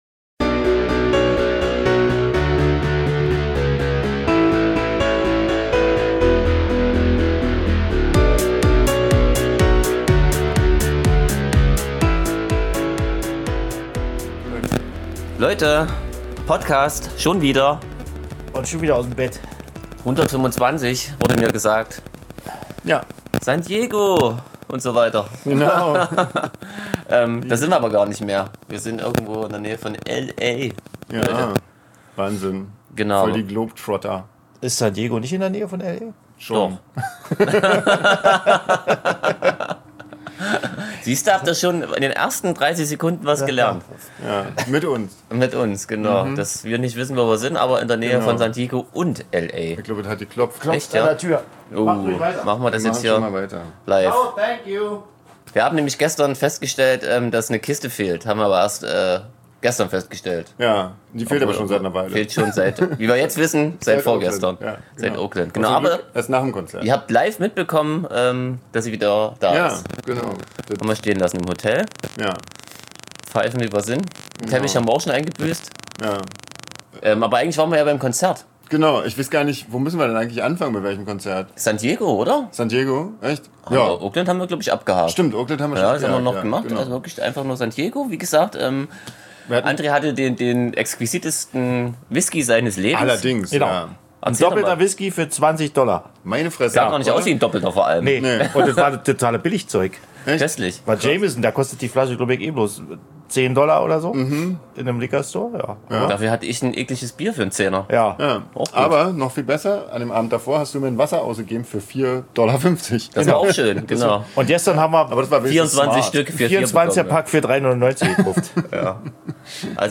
Wir berichten weiter von unserer Nordamerika-Tour, dieses Mal von den Konzerten, dem Drumherum und natürlich allerlei Köstlichkeiten in San Diego, Las Vegas, Los Angeles, Parsippany und Washington DC. Es gibt am Anfang ein paar Tonstörungen, sorry dafür.